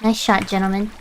Worms speechbanks
Missed.wav